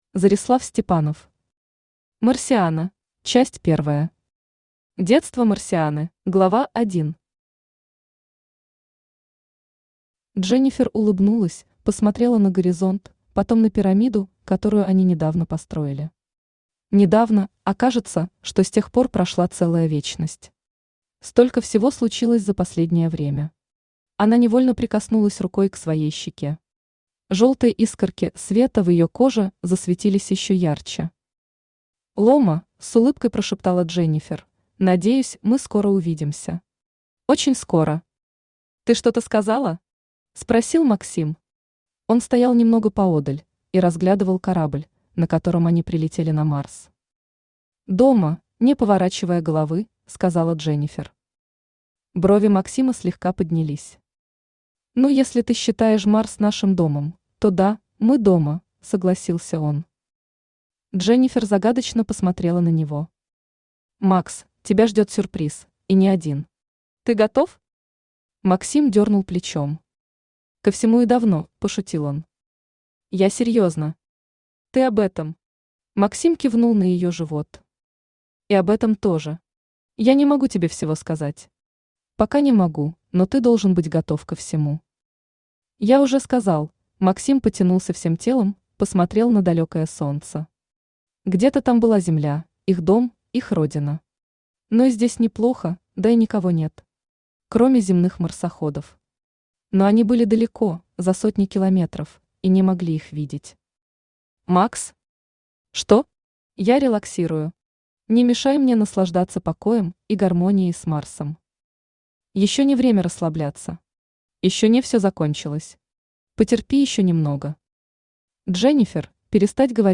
Аудиокнига Марсиана | Библиотека аудиокниг
Aудиокнига Марсиана Автор Зореслав Степанов Читает аудиокнигу Авточтец ЛитРес.